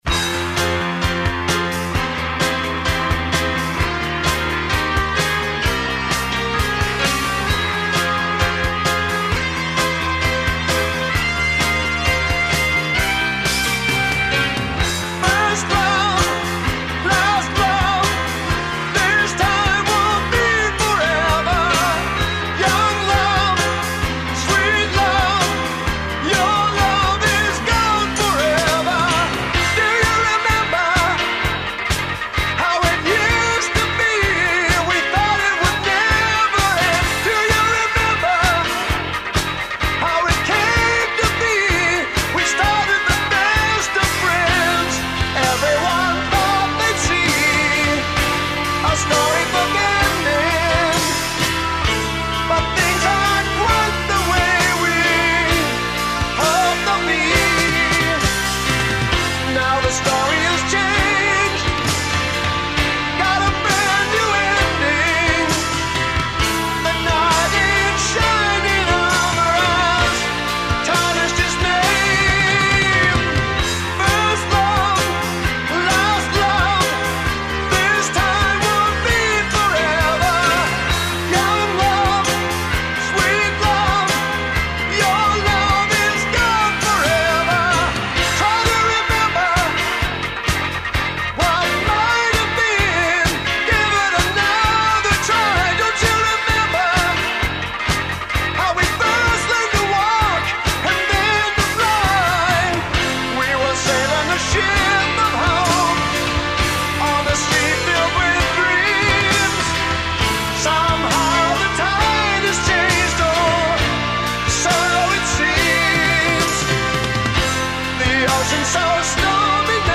This has a kind of Procol Harem with the Edmonton Symphony sound to it which is kind of overblown but oh well..